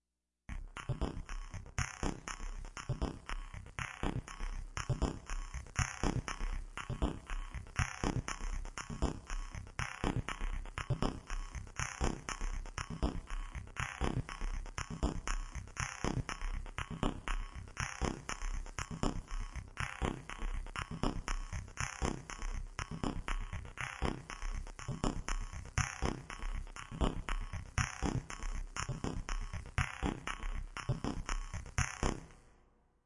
这是一个通过fizmo的声码器效果运行的补丁。没有外部处理。
Tag: 数字 ENSONIQ fizmo 合成器 语音编码器